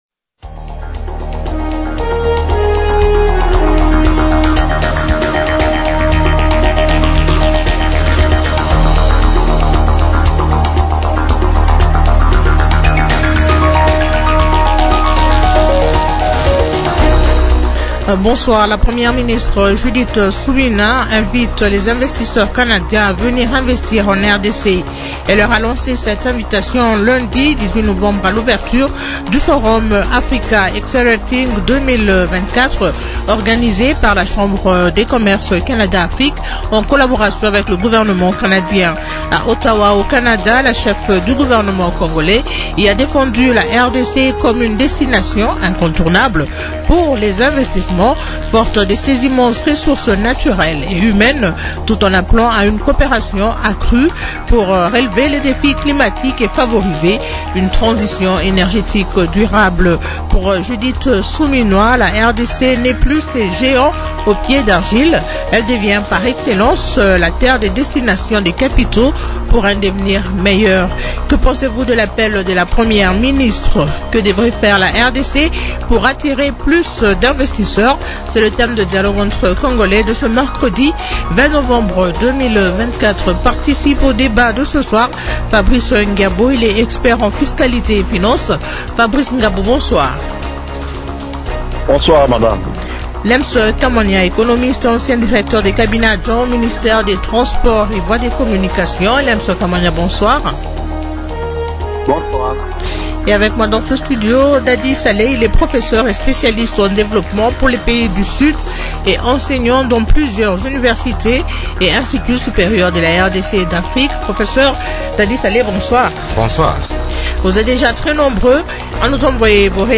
expert en fiscalité et finances